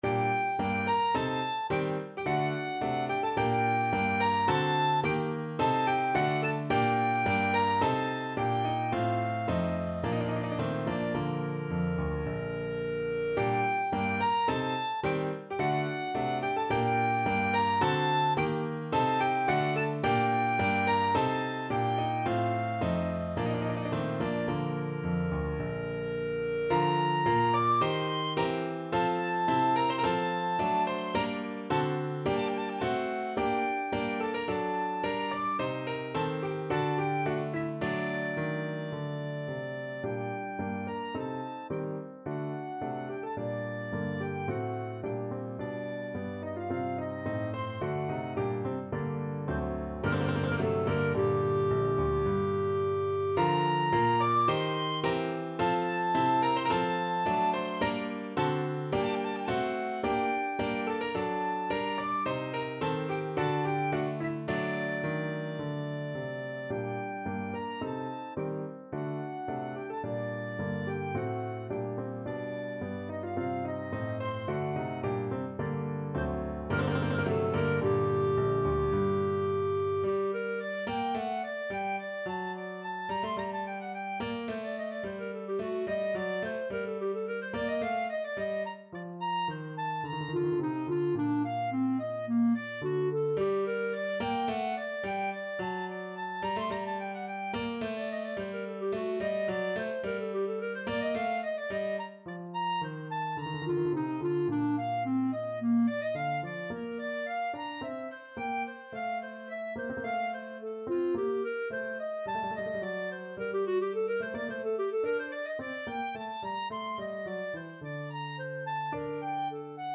Clarinet
G minor (Sounding Pitch) A minor (Clarinet in Bb) (View more G minor Music for Clarinet )
3/4 (View more 3/4 Music)
~ = 54 Moderato
Classical (View more Classical Clarinet Music)